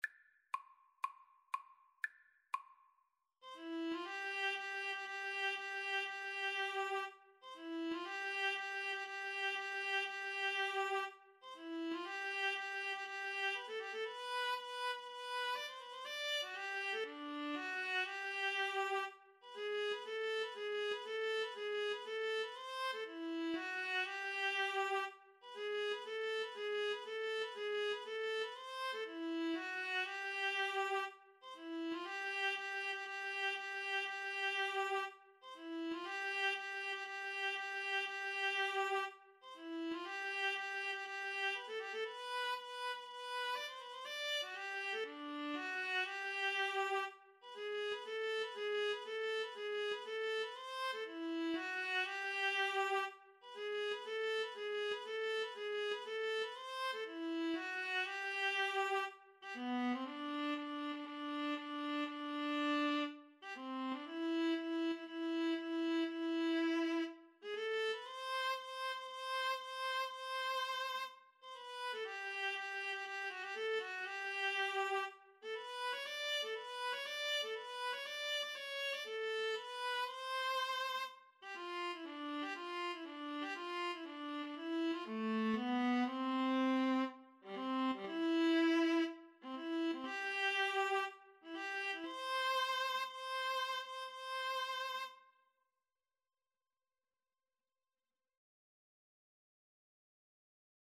Maestoso =120
Classical (View more Classical Viola-Cello Duet Music)